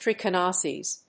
音節trich・i・no・sis 発音記号・読み方
/trìkənóʊsɪs(米国英語)/